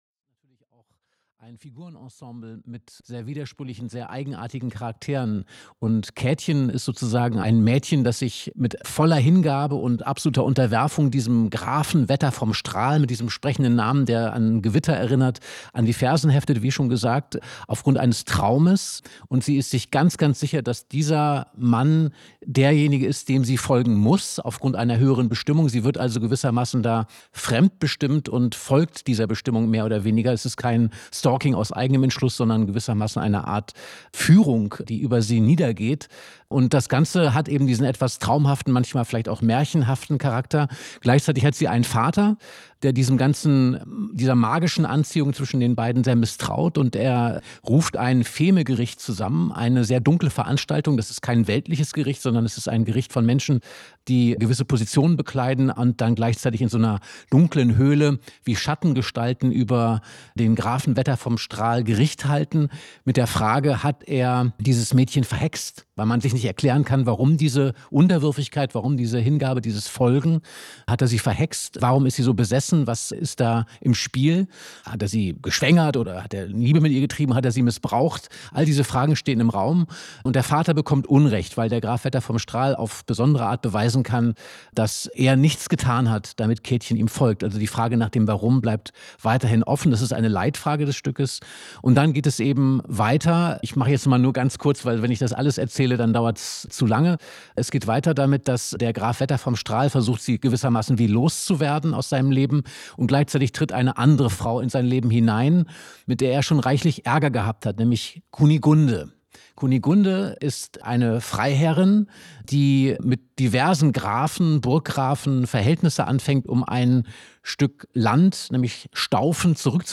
Mitarbeit Interviewte Person: Ulrike Draesner, John von Düffel
Live